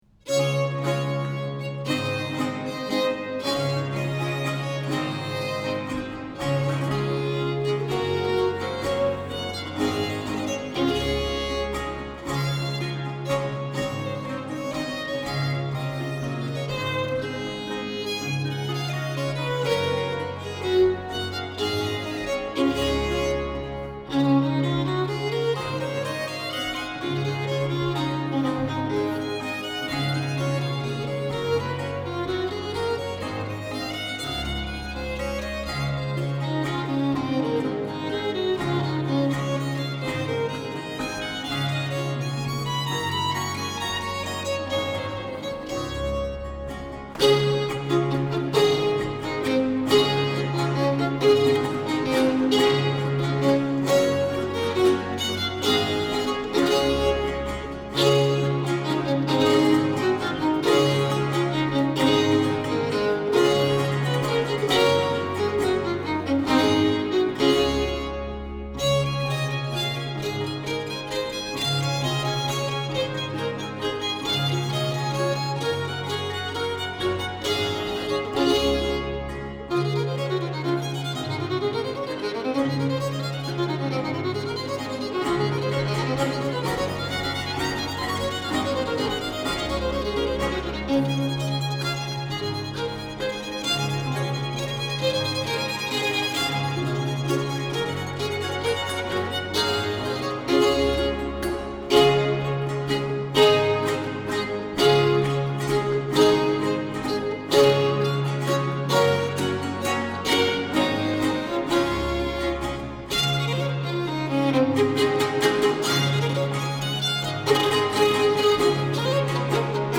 lute
chitarra attiorbata
colascione
Here you can convince yourself of the effect of the Salzburg lute continuo, with an Aria from the Sonata 14.